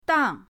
dang4.mp3